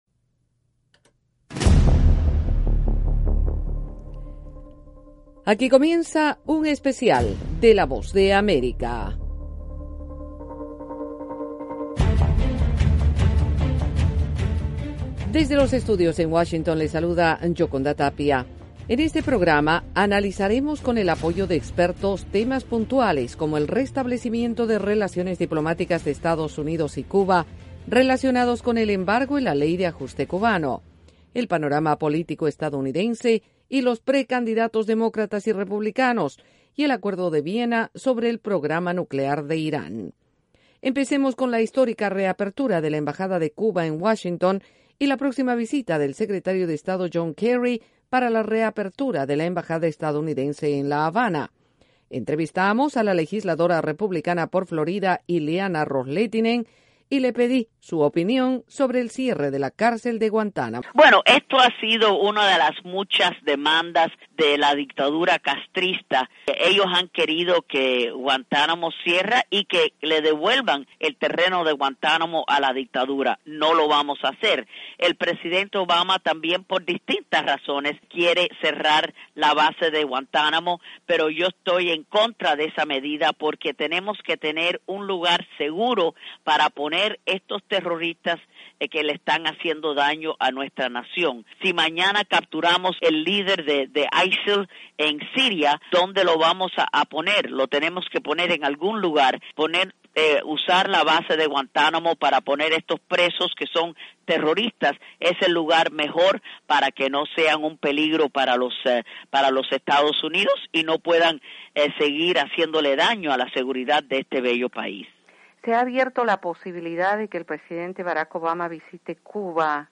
En este programa de 30 minutos, la Voz de América explora con expertos, temas puntuales como el restablecimiento de las relaciones diplomáticas de EE.UU. y Cuba, el acuerdo de Viena sobre el programa nuclear de Irán y el panorama político de EE.UU.